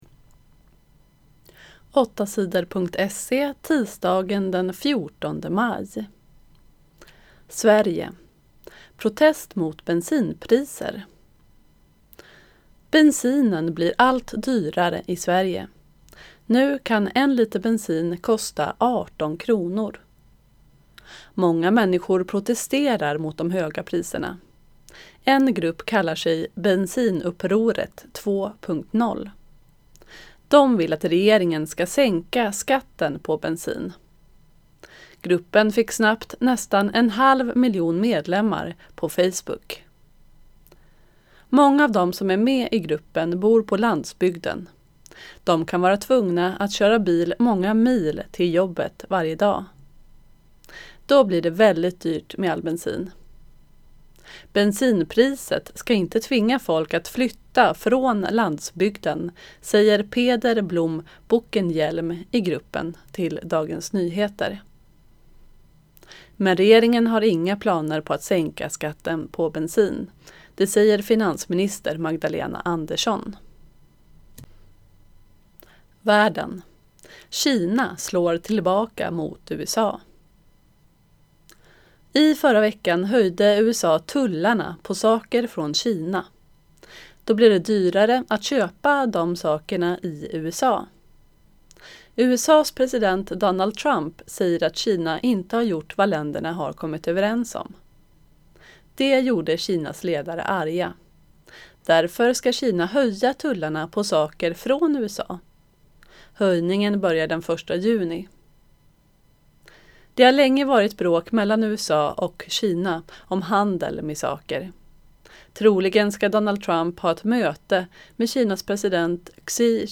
Inlästa nyheter den 14 maj